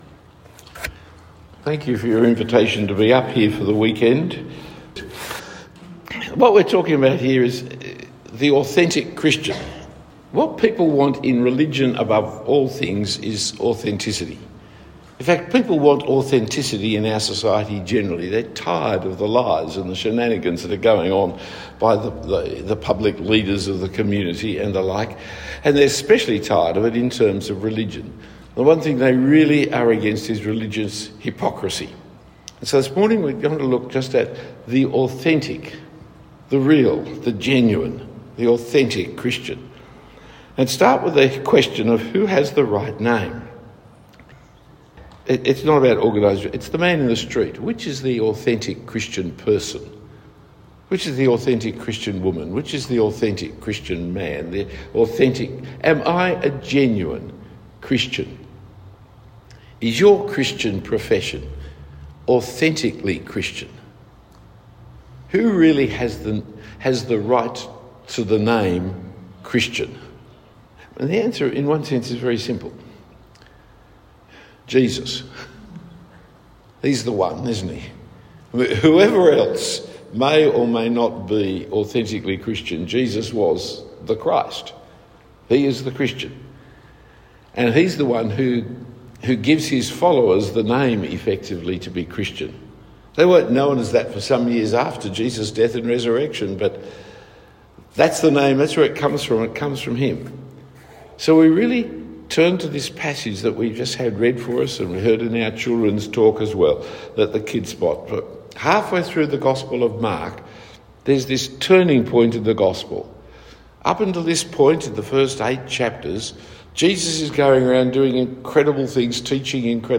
A sermon at ChristLife Presbyterian Church Toowoomba.